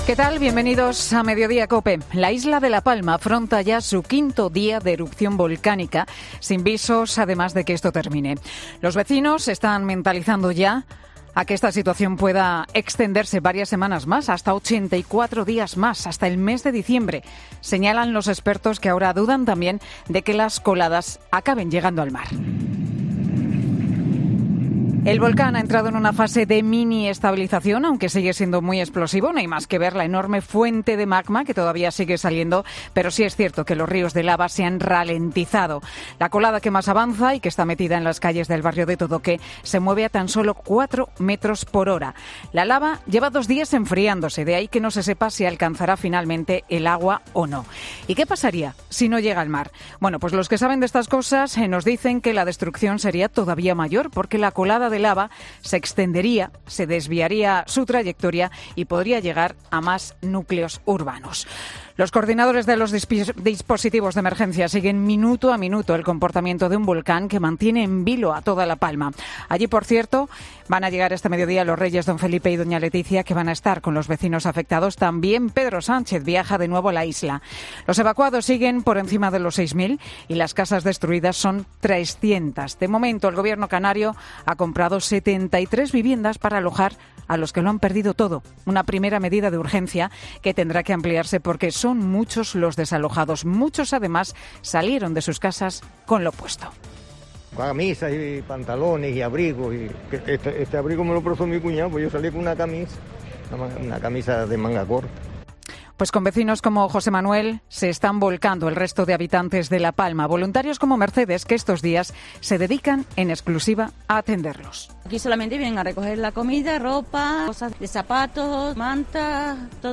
Monólogo de Pilar García Muñiz